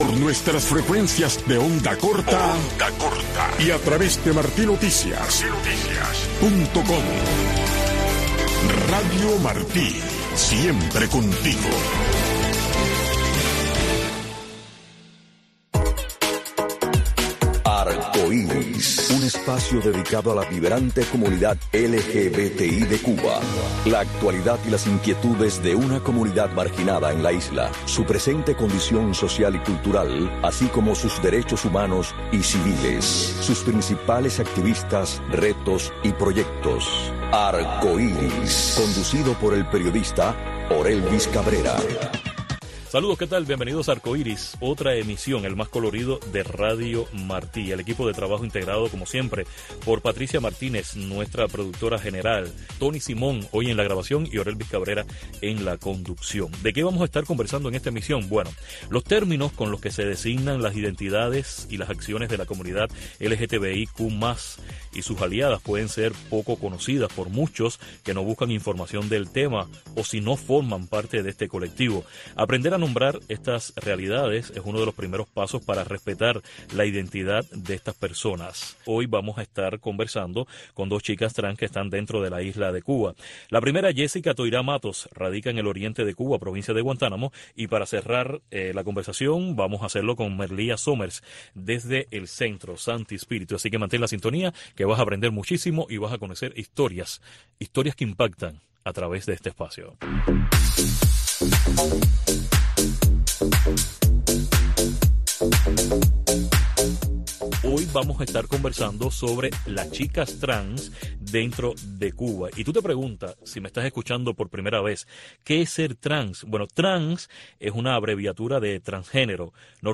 Hoy nos acompañaron dos mujeres Trans de la zona del Oriente de Cuba